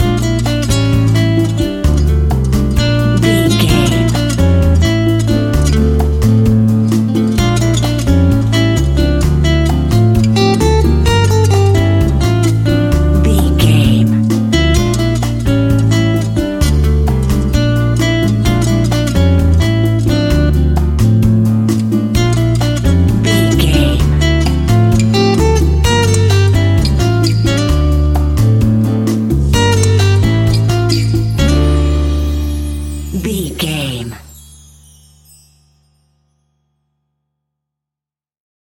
An exotic and colorful piece of Espanic and Latin music.
Aeolian/Minor
romantic
maracas
percussion spanish guitar